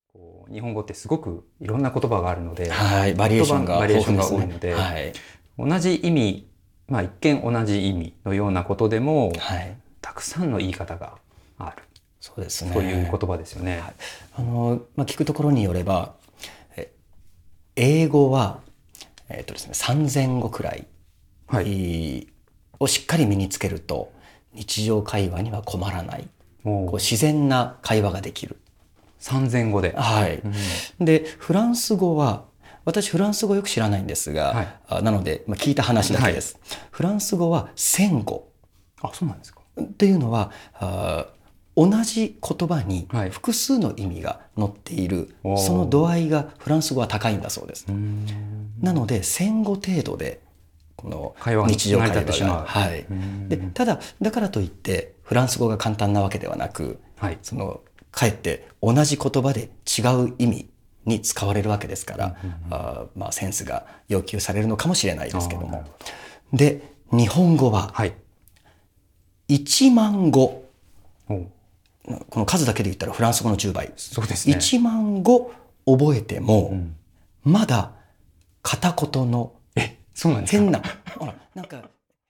それでは、音声講座でお会いしましょう。